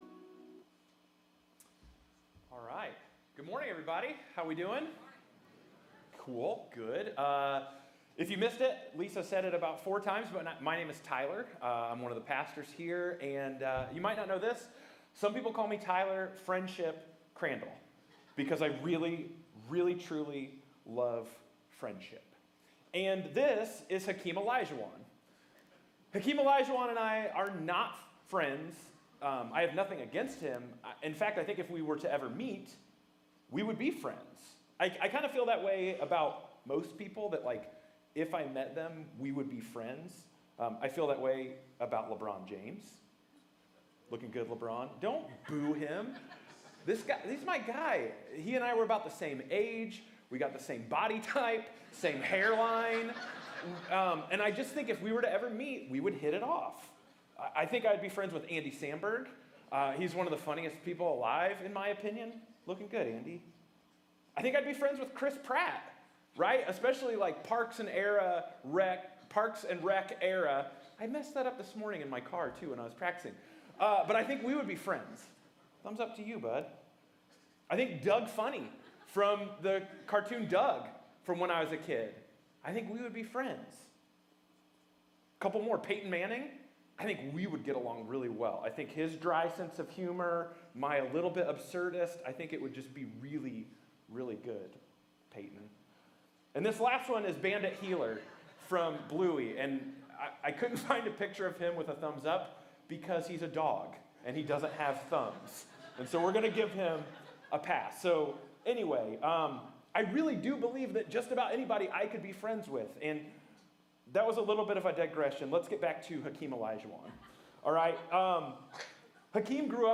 Latest Sermon – Commonway Church